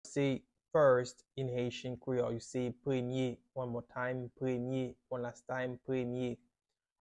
How to say “First” in Haitian Creole – “Premye” pronunciation by a native Haitian teacher
“Premye” Pronunciation in Haitian Creole by a native Haitian can be heard in the audio here or in the video below: